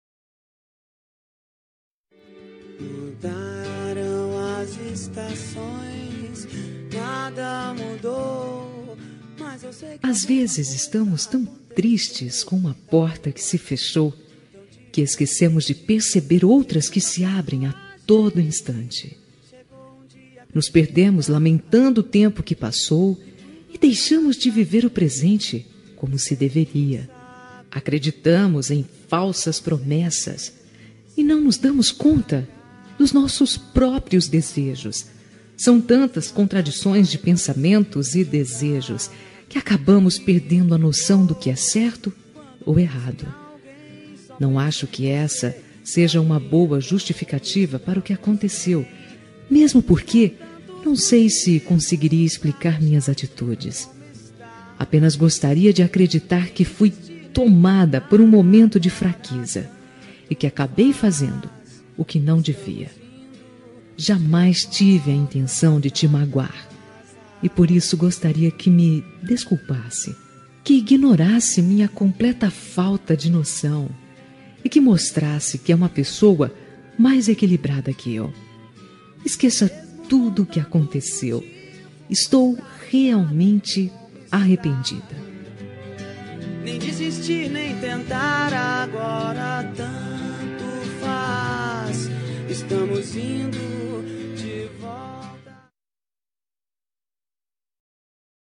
Reconciliação Amizade – Voz Feminina – Cód: 036794